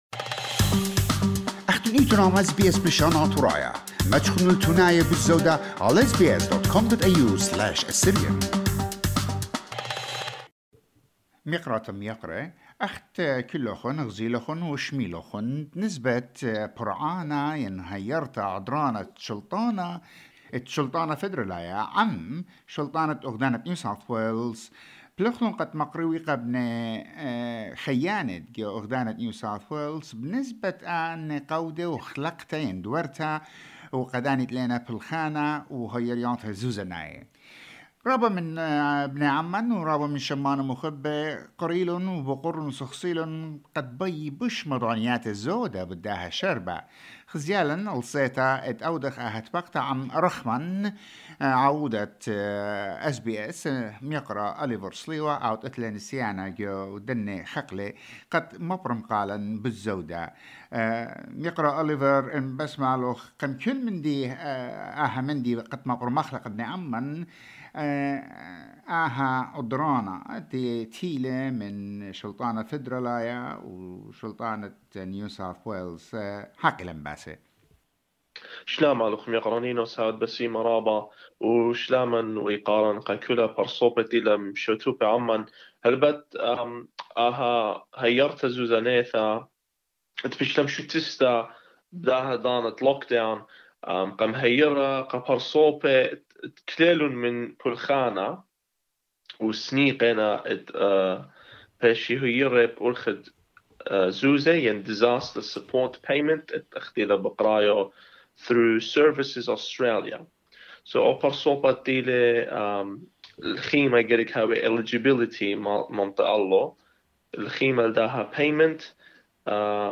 These are the topics discussed in the interview, we have provided you with most of the links relevant to the support payment.